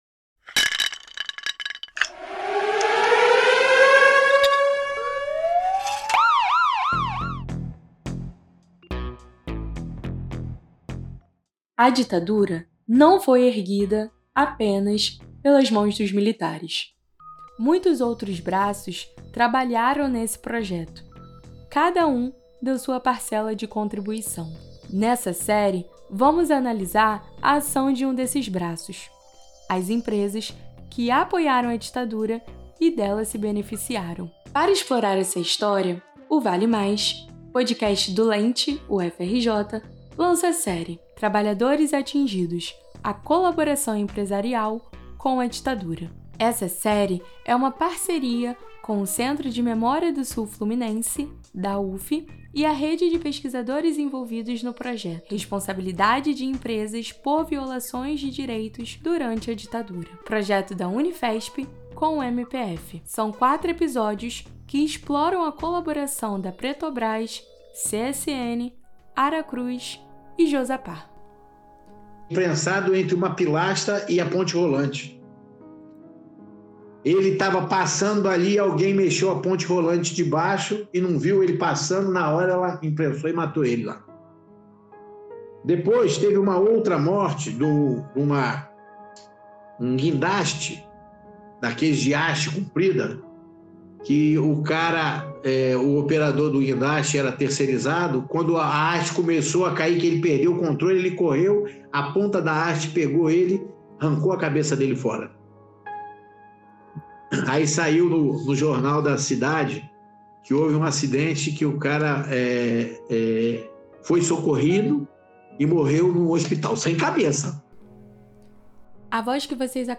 Entrevista com trabalhadores atingidos